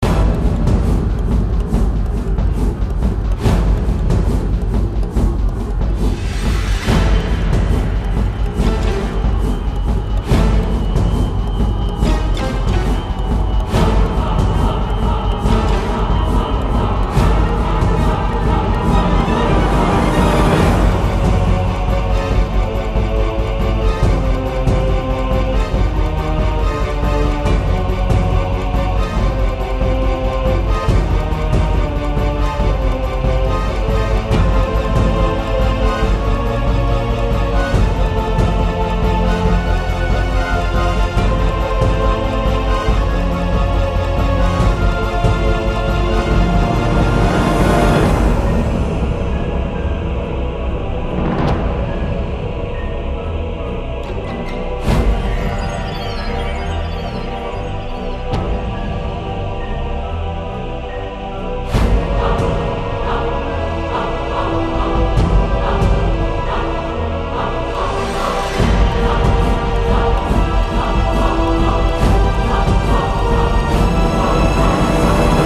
Еще одна боевая мелодия Джиграна.